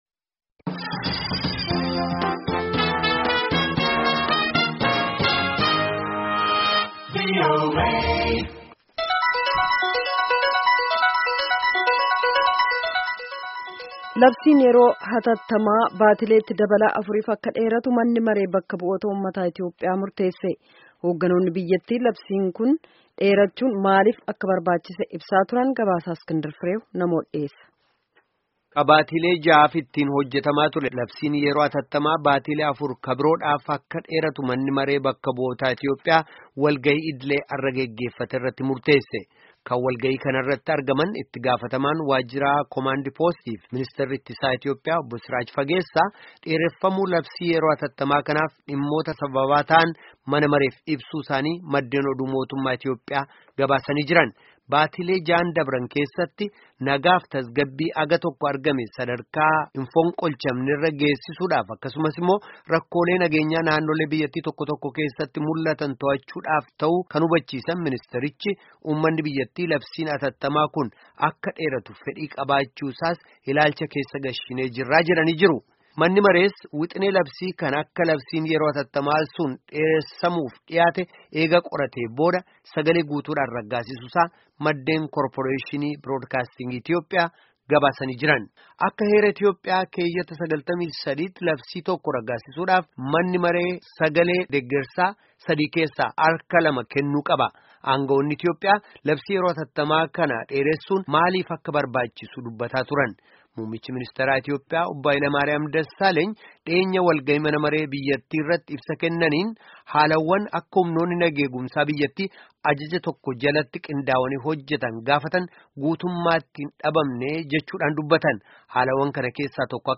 Guutummaa gabaasichaa fi yaada dhaggeeffatootaa kana cuqaasuun dhaggeeffadhaa